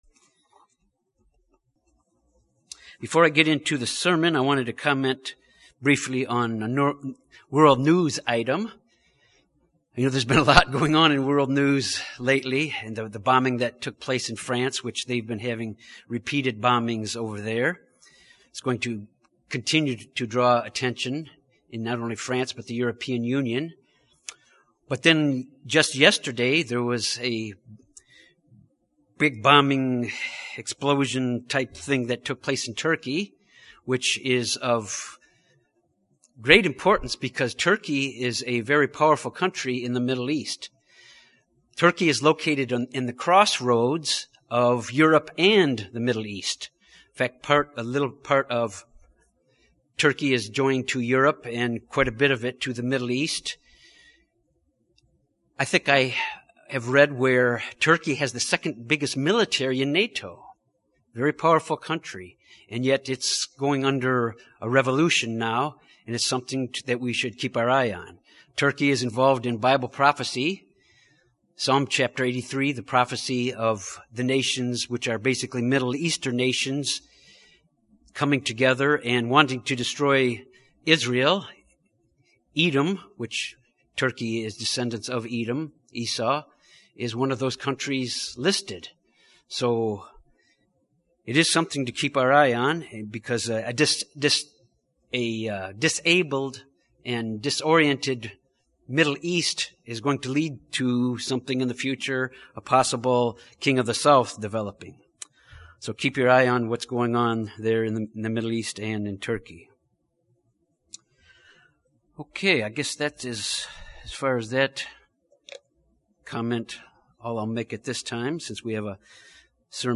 This sermon covers the last three I AM statements of Jesus Christ in the Gospel of John. These statements of Jesus Christ are important to us because they reveal His character attributes.